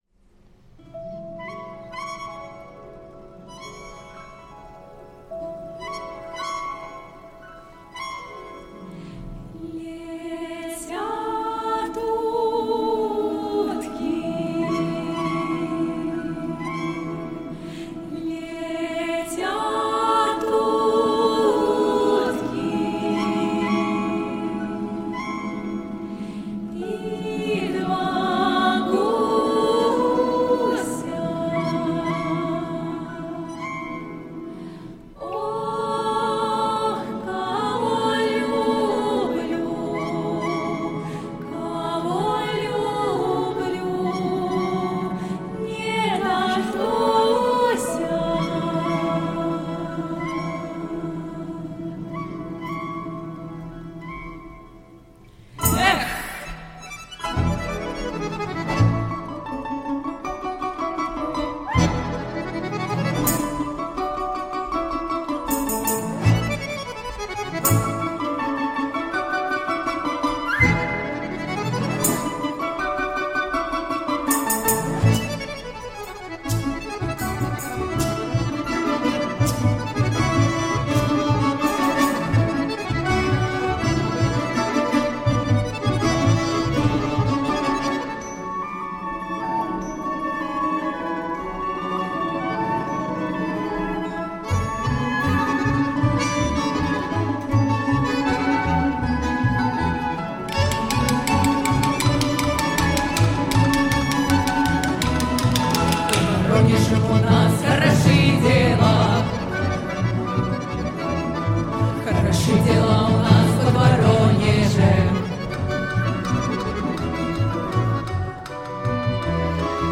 - весёлая увертюра